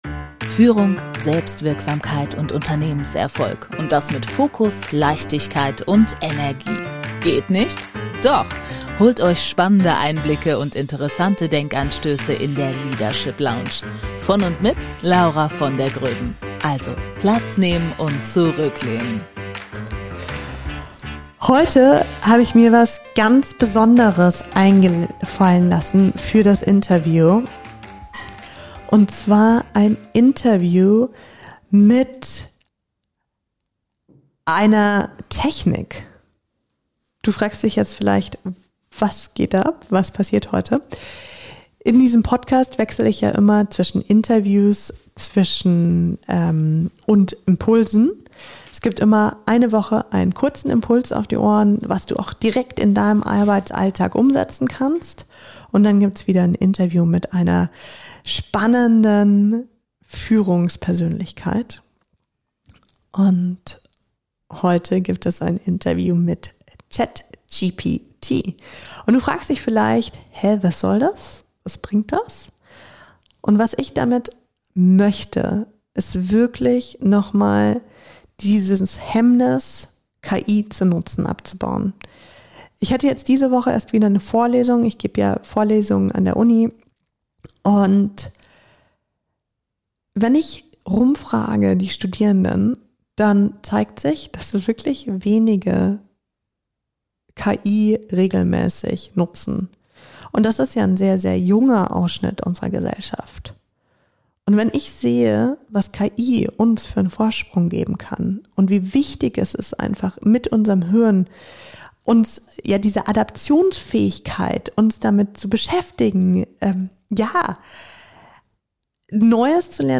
Hör rein und finde heraus, wie sich ein Interview mit KI wirklich anfühlt!